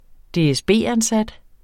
Udtale [ -anˌsad ]